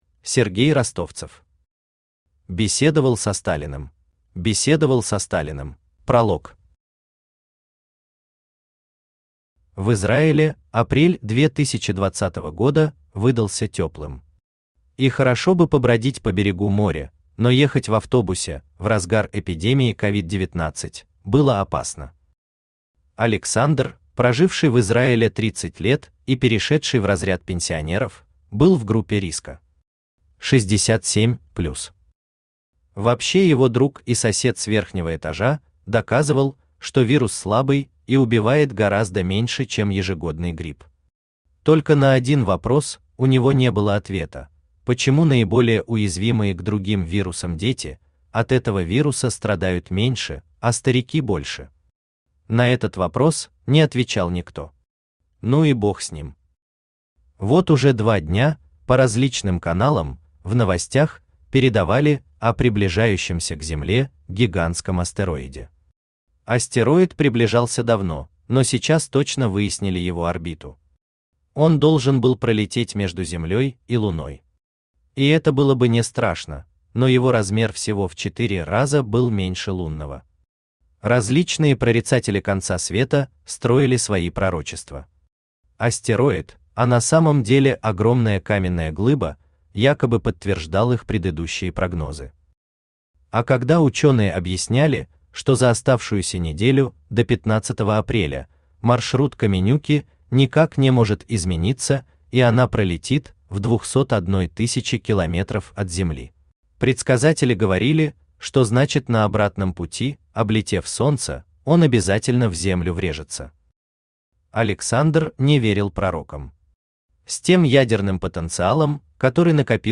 Аудиокнига Беседовал со Сталиным | Библиотека аудиокниг
Aудиокнига Беседовал со Сталиным Автор Сергей Юрьевич Ростовцев Читает аудиокнигу Авточтец ЛитРес.